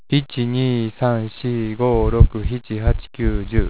ためになる広島の方言辞典 は．
ちょっと自信がありませんが、広島では「ひち」と言ってる人が結構いるのではないでしょうか？
hichi.wav